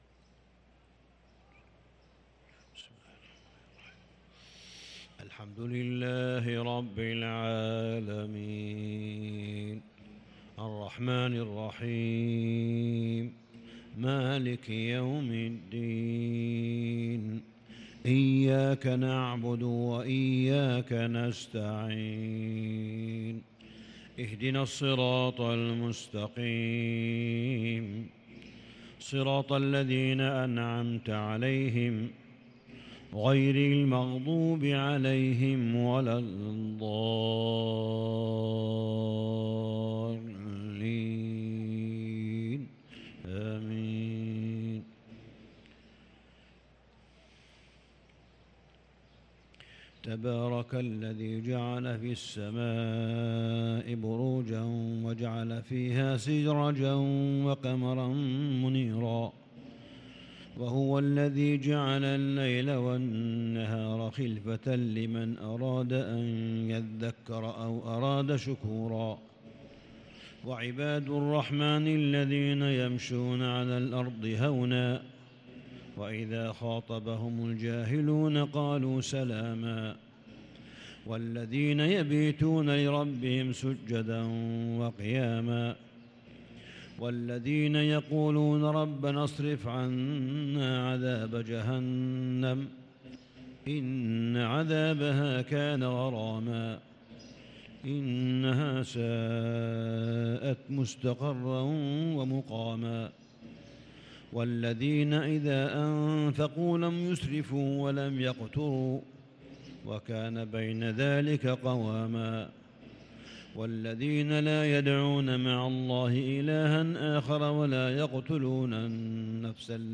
صلاة الفجر للقارئ صالح بن حميد 29 رمضان 1443 هـ
تِلَاوَات الْحَرَمَيْن .